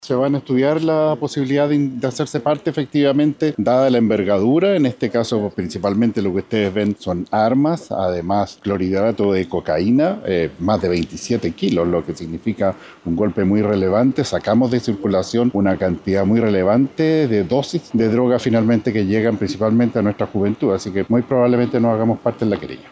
Por otro lado, el delegado presidencial de Valparaíso, Yanino Riquelme, señaló que están evaluando presentar una querella, dada la envergadura de los elementos incautados.